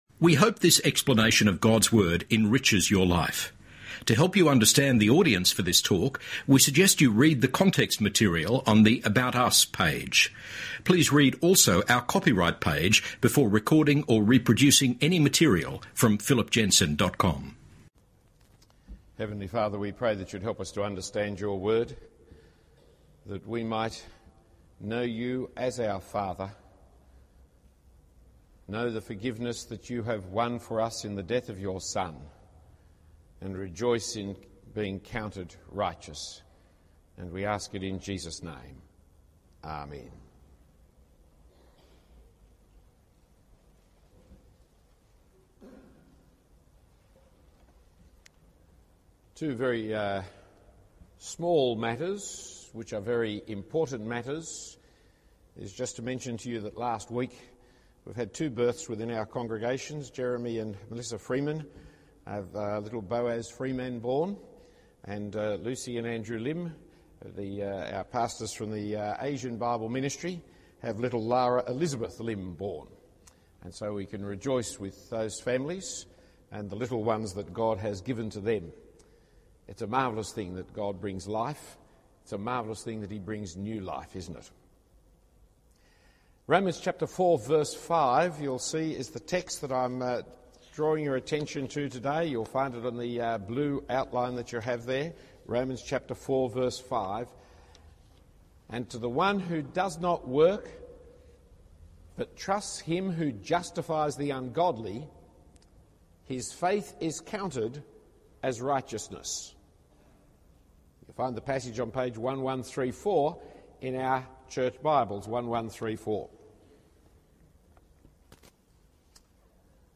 Romans 2006-7 St Andrews Cathedral